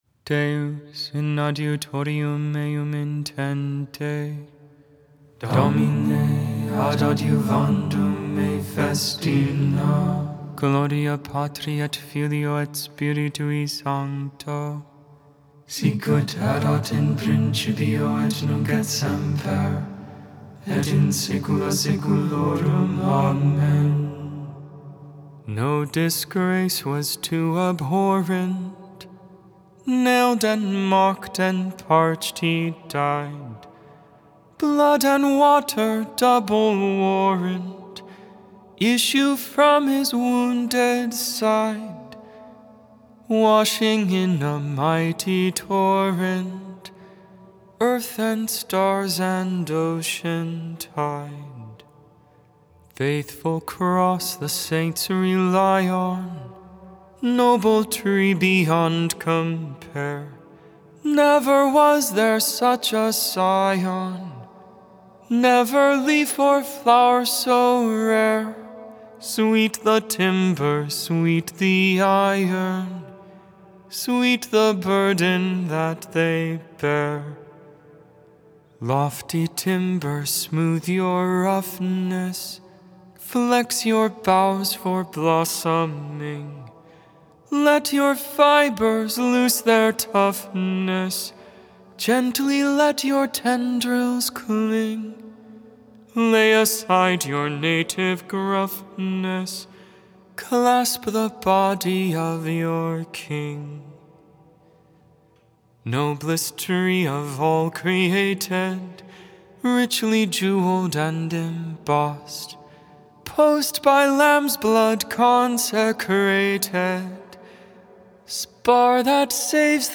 The Liturgy of the Hours: Sing the Hours 3.28.24 Lauds, Thursday Morning Prayer Mar 27 2024 | 00:17:24 1x 00:00 / 00:17:24 Subscribe Share Spotify RSS Feed Share Link Embed